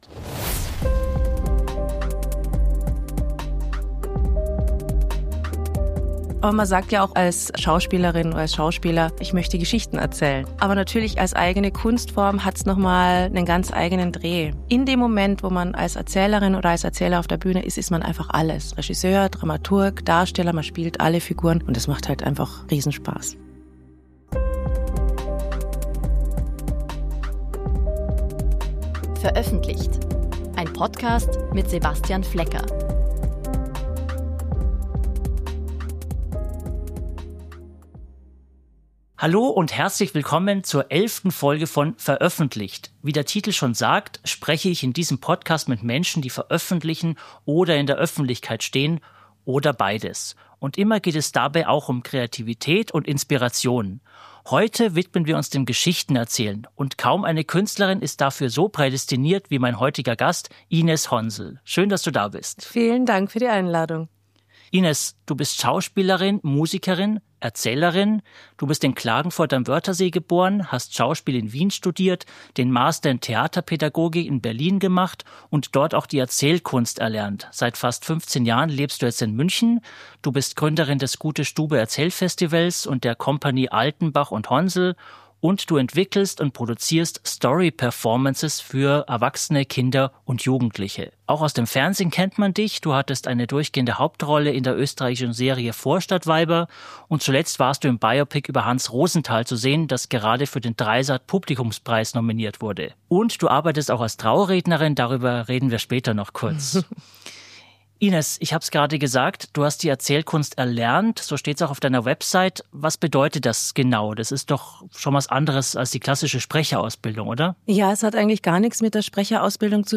Ein Gespräch über die Welt des freien Erzählens: wie lebendige Geschichten entstehen, weshalb Wohnzimmer manchmal die besseren Bühnen sind – und wie sich Schauspiel, Musik und Erzählen zu einer eigenen Kunstform verweben.